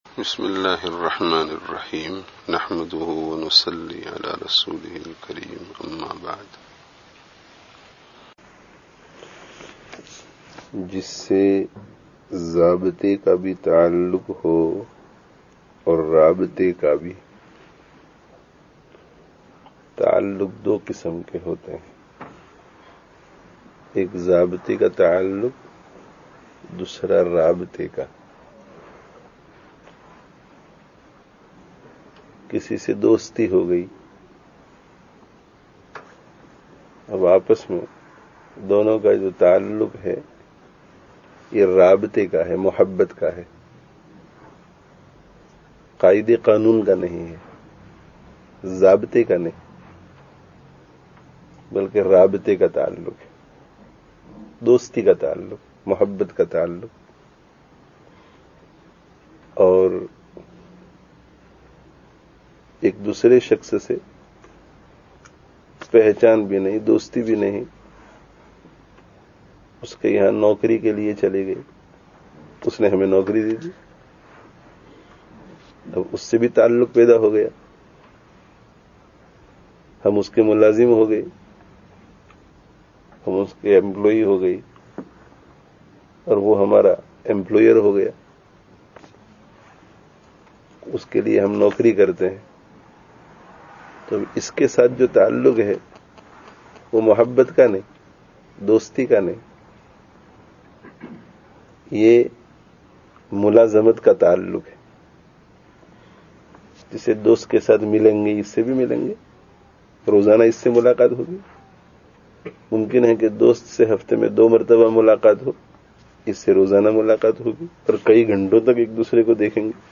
Rābtah awr Zābtah kā Ta'alluq [after Jumu'ah] (21/01/11)